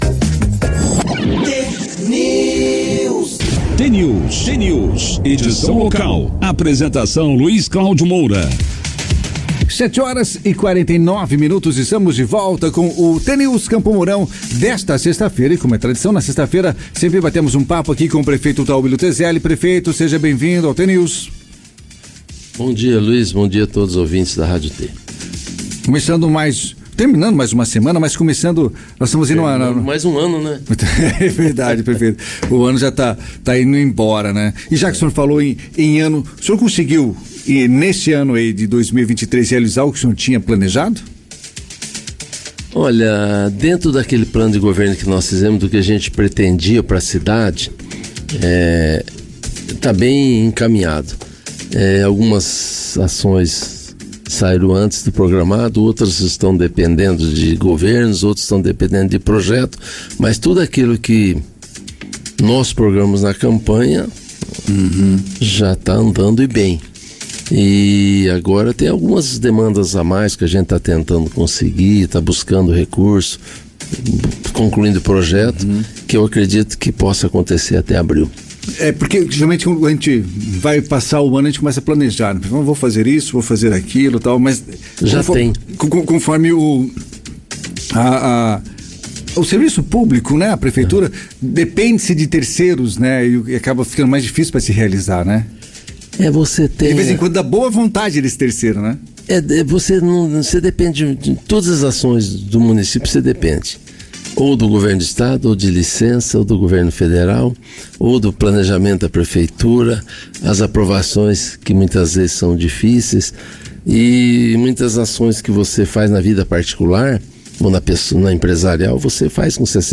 Como faz já há mais de duas décadas, Tauillo Tezelli, atual prefeito de Campo Mourão, participou nesta sexta-feira, dia 13, do jornal T News, da Rádio T FM.
Burocracia (exigências documentais) atrasando início de obras, desassoreamento do Parque do Lago e revitalização do Parque do Exposição, alguns dos temas tratados no programa. Clique no player abaixo e ouça a íntegra da entrevista.